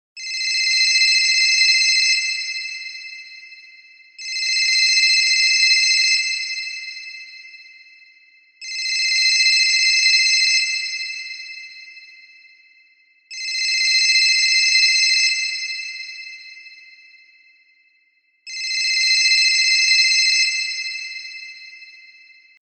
classic-old-phone_24703.mp3